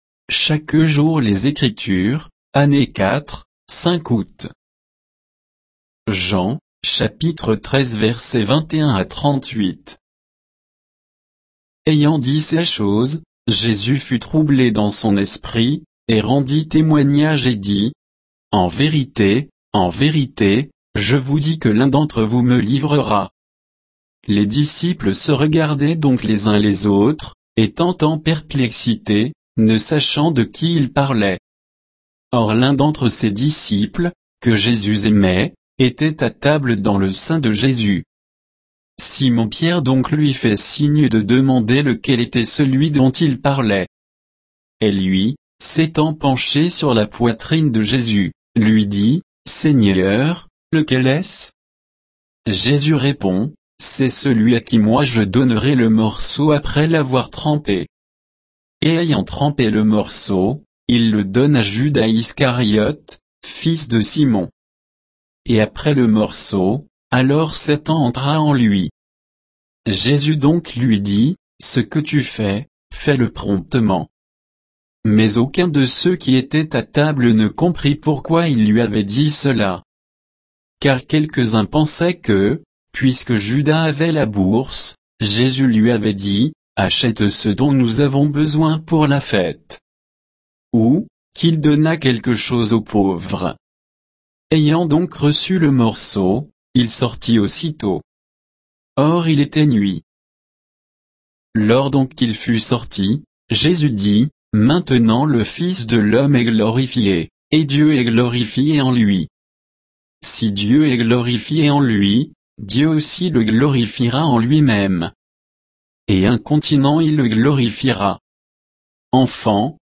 Méditation quoditienne de Chaque jour les Écritures sur Jean 13, 21 à 38